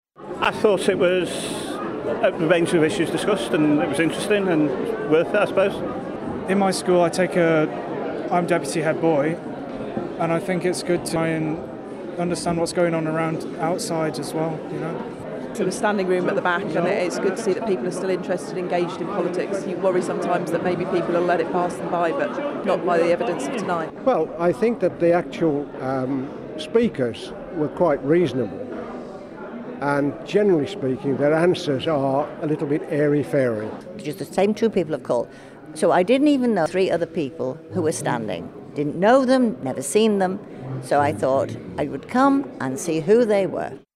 Here's what some of those who attended thought of the meeting: Listen to this audio